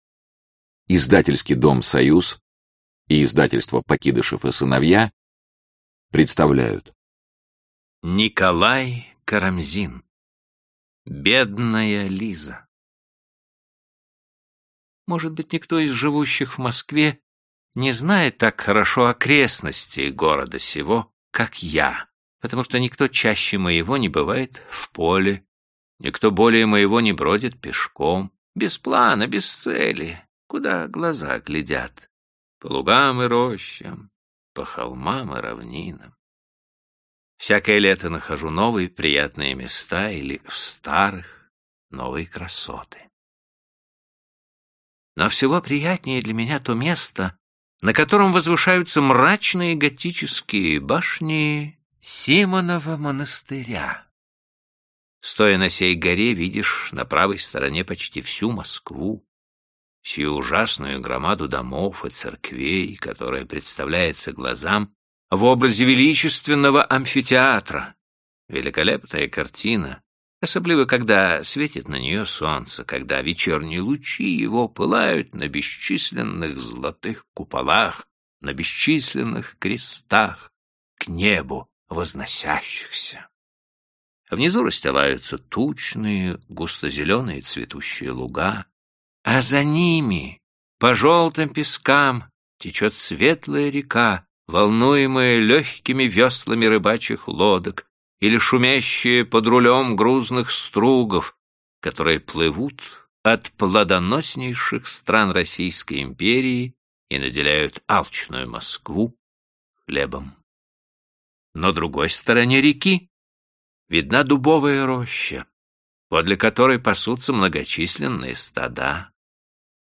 Аудиокнига Бедная Лиза | Библиотека аудиокниг